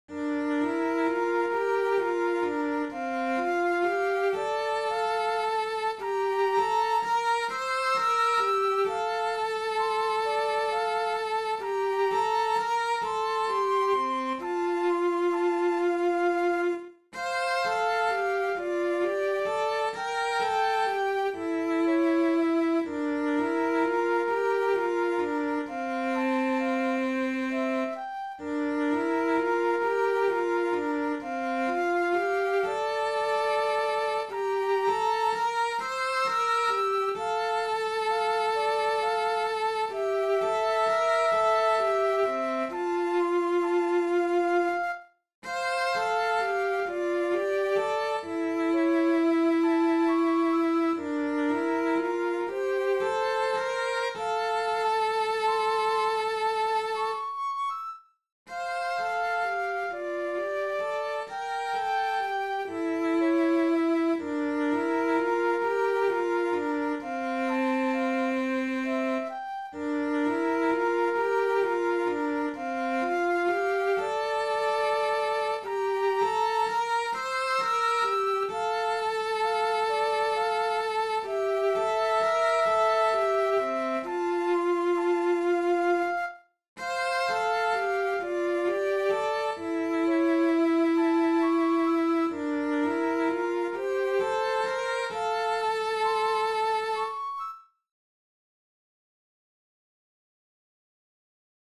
Siivekas-soutaja-sello-ja-huilu.mp3